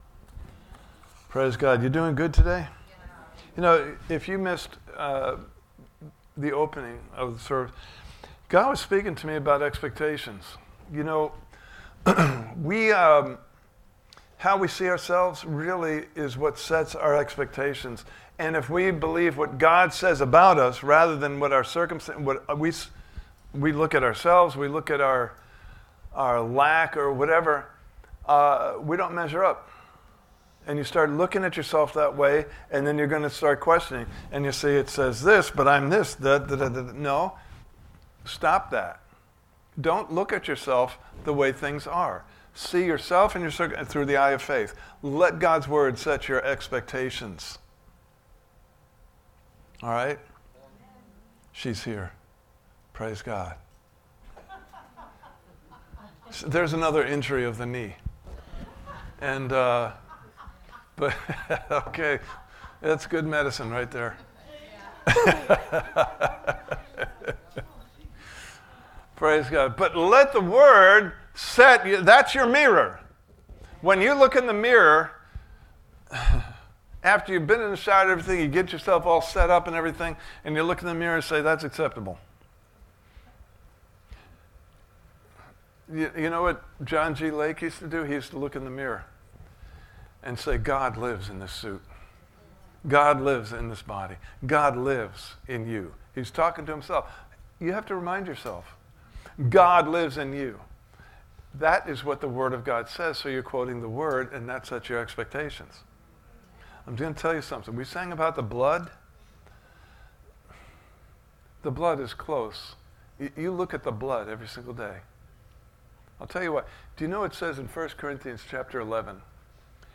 Prepared Service Type: Sunday Morning Service « Part 2